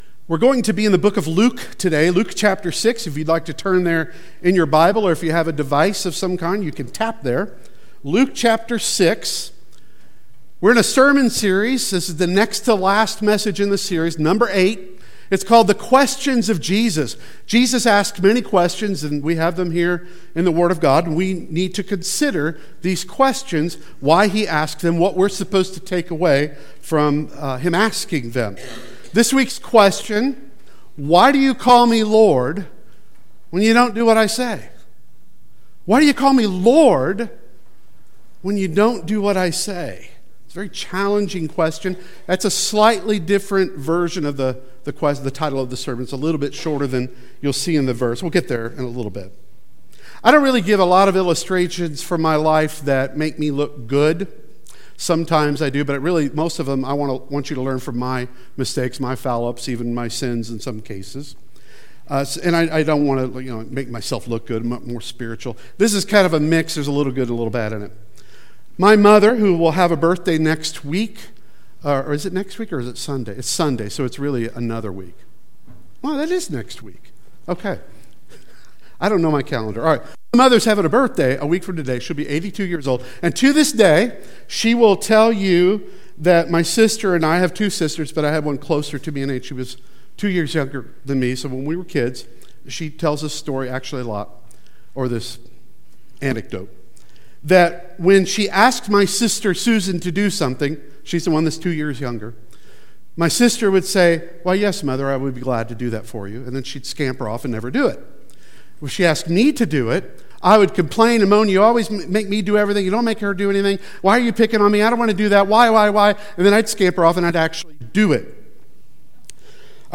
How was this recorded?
The Questions of Jesus Service Type: Sunday Worship Service Speaker